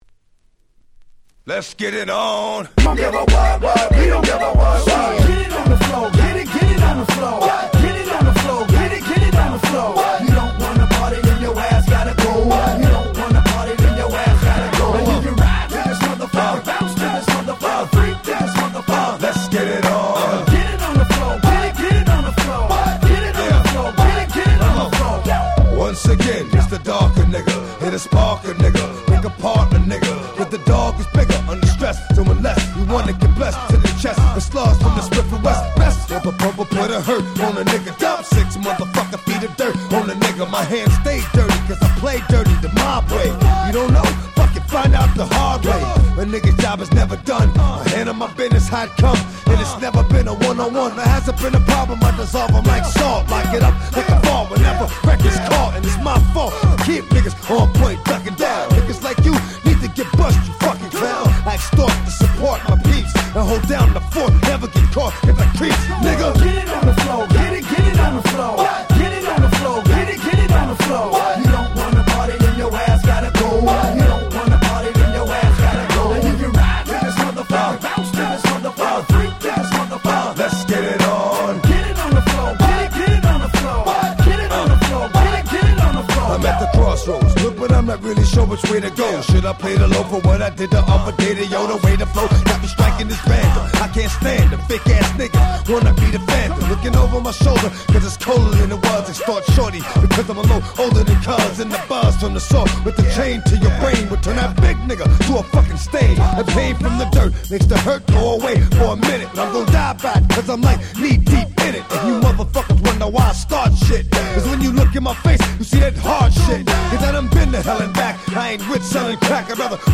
03' Smash Hit Hip Hop !!